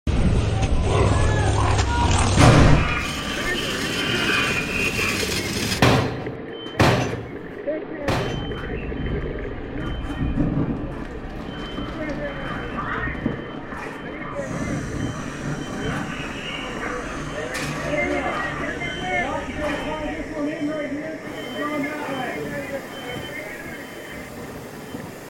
WW2, 1944: F6F Hellcat Crash Sound Effects Free Download